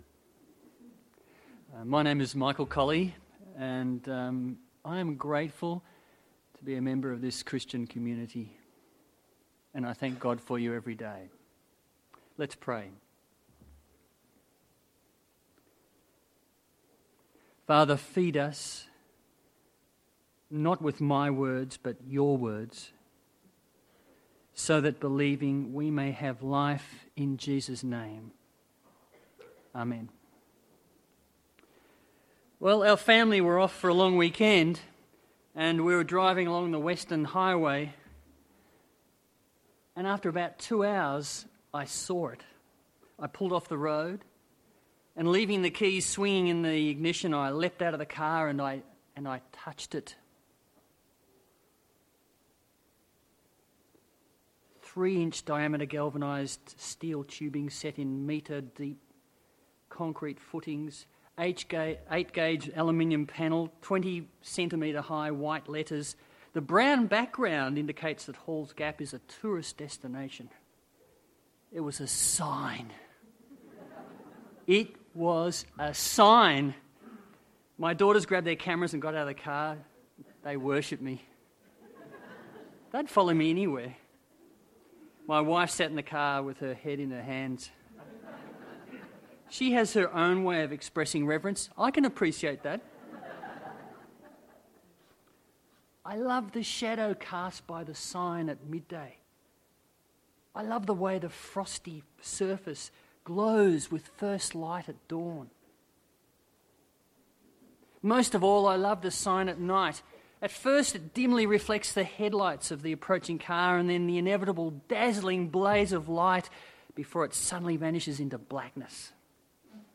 Current Sermon
Guest Speaker